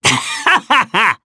Gladi-Vox-Laugh_jp.wav